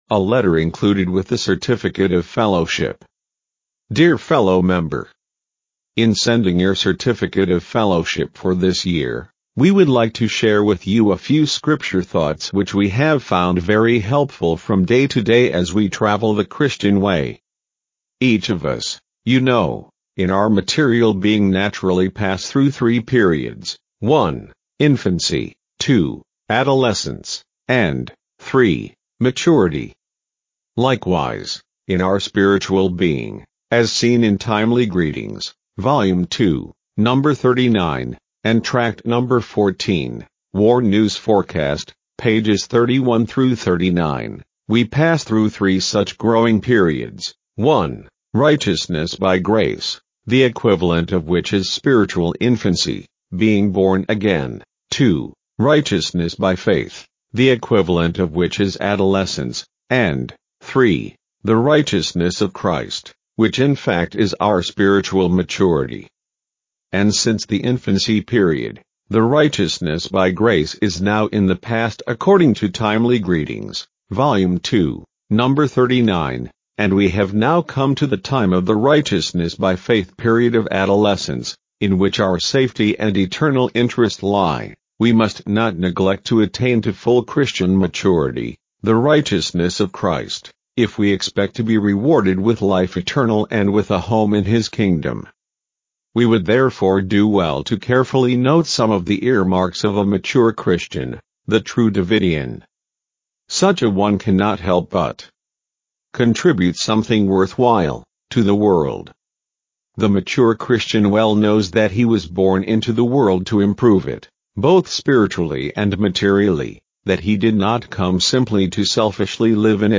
fellowship-letter-male.mp3.mp3